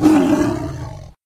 CosmicRageSounds / ogg / general / combat / creatures / tiger / she / attack1.ogg
attack1.ogg